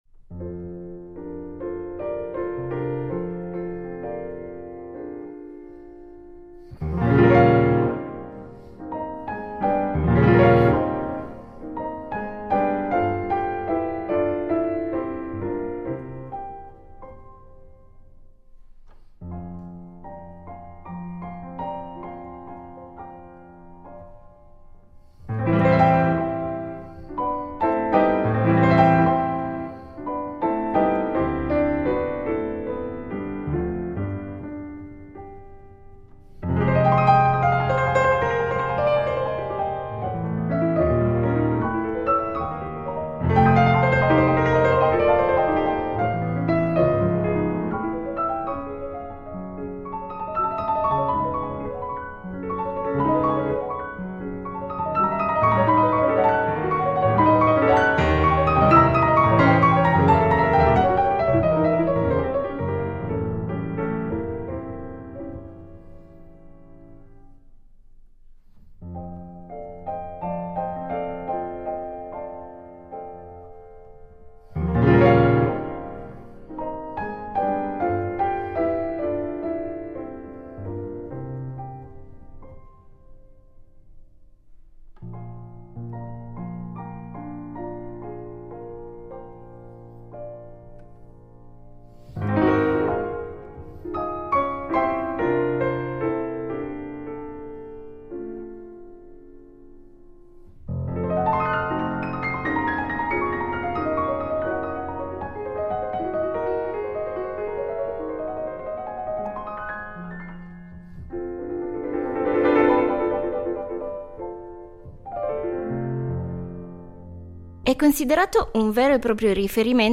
A colloquio con Bertrand Chamayou